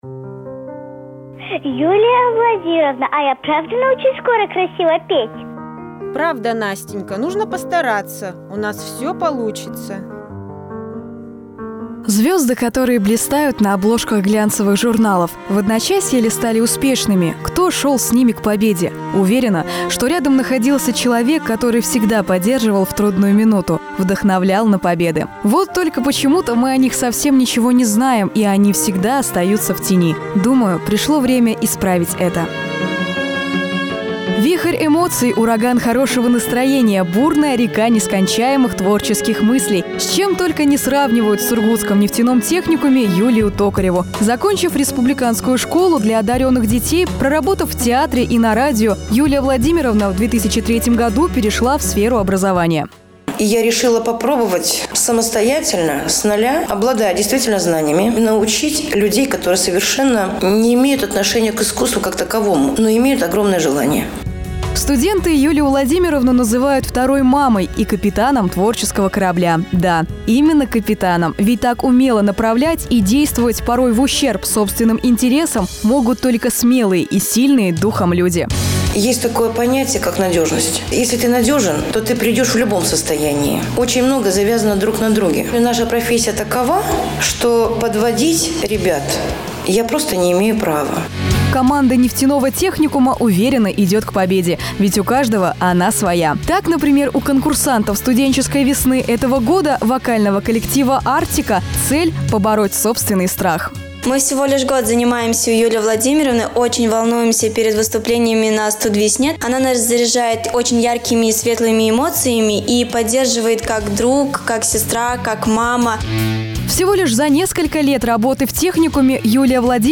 Радиоэфир